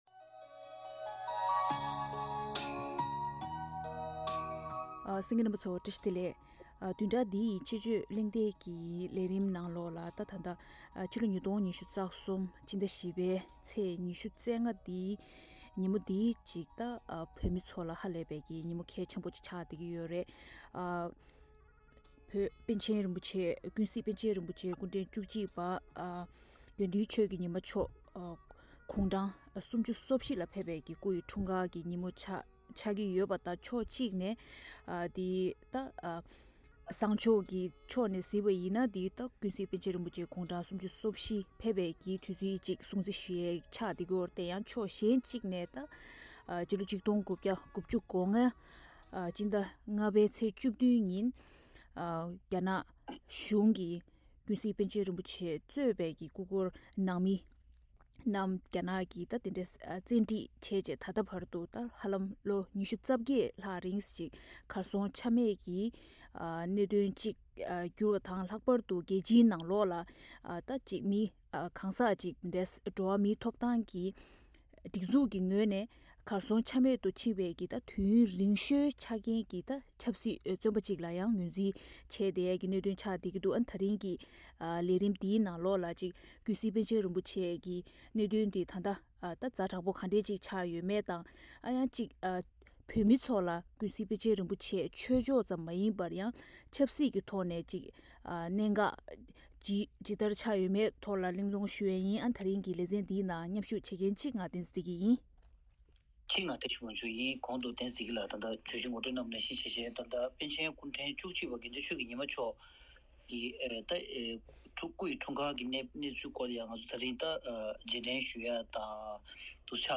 དཔྱད་གཞིའི་གྲོས་བསྡུར་ཞུས་པ་ཞིག་གསན་རོགས་གནང་།